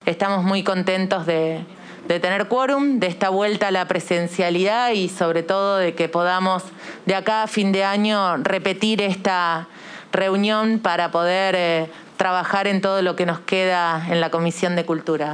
Audio de la diputada nacional Gisela Scaglia: